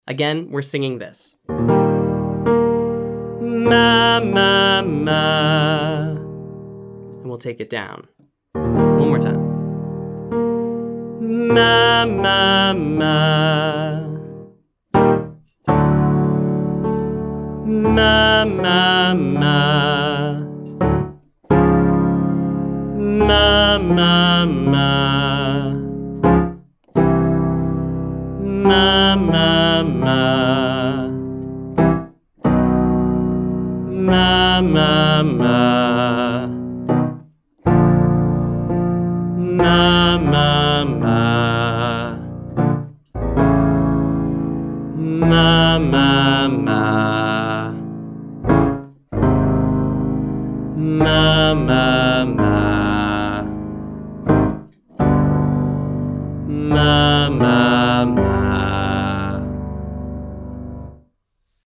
So in general, use a relaxed, medium-volume chest voice sound and allow your larynx to float and stay flexible and open.
I’ll sing as low as I can as a Tenor and then continue to play the piano a little lower for any Basses out there.